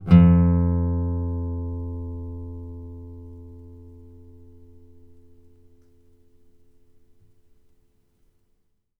bass-10.wav